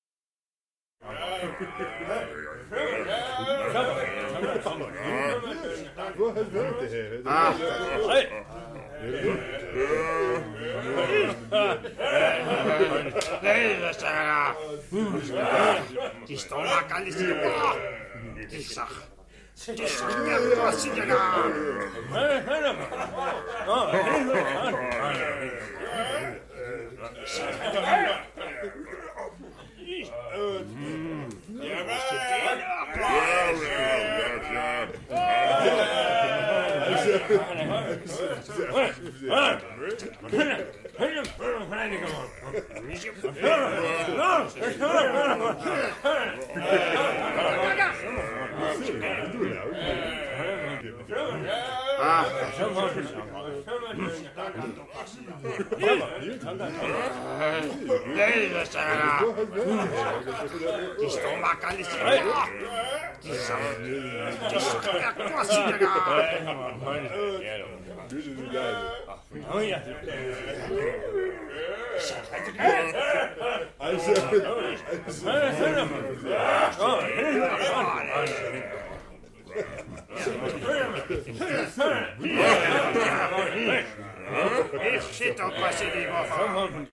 intavern.mp3